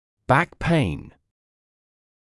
[bæk peɪn][бэк пэйн]боль в спине